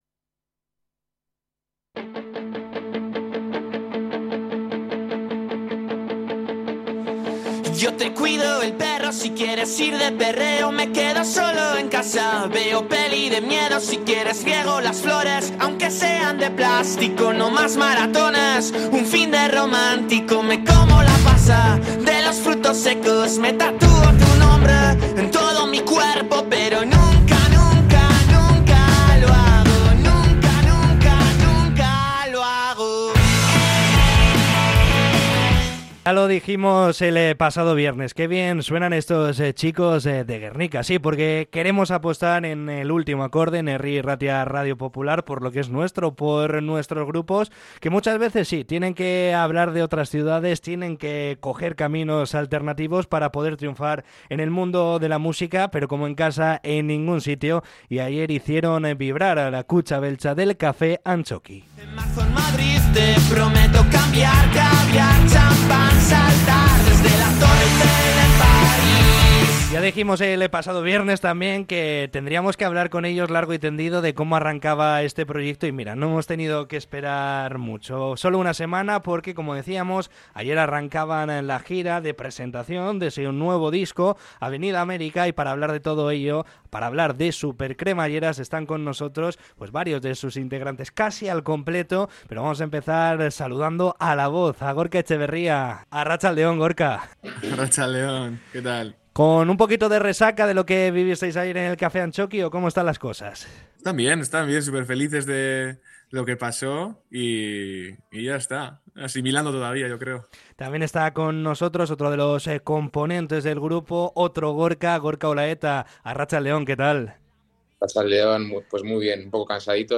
Entrevista con el grupo Supercremalleras tras inaugurar la gira en la Kutxa Beltza del Kafe Antzokia